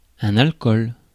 ÄäntäminenFrance (Paris):
• IPA: [ɛ̃.n‿al.kɔl]